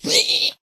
zpighurt2.ogg